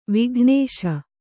♪ vighnēśa